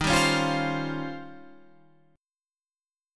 Eb13 chord